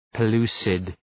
Προφορά
{pə’lu:sıd}